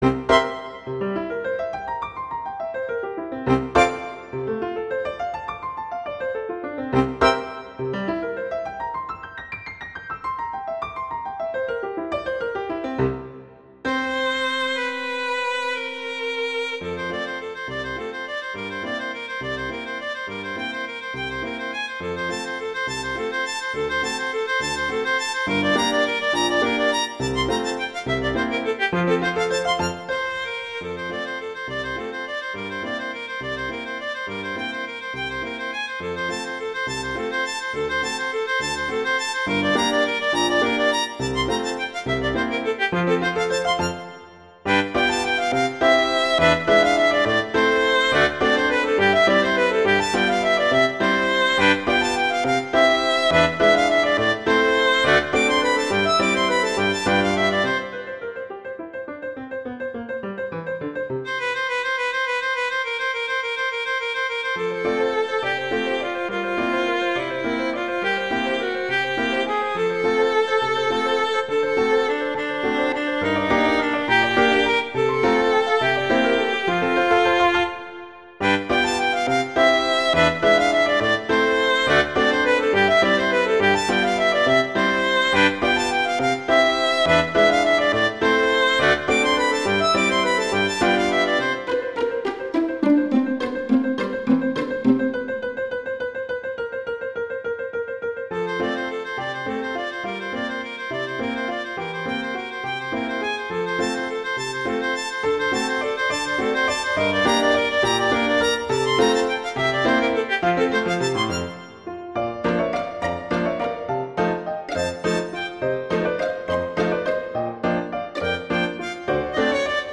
classical, french
F major
♩=208 BPM